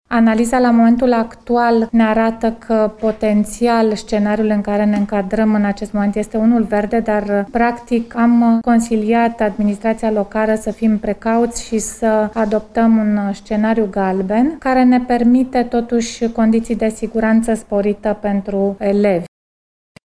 Scenariul pe baza căruia autoritatile hotarasc revenirea la școală este luat în funcție de numărul de îmbolnăviri, susține directorul Direcției de Sănătate Publică Brasov, Andrea Neculau.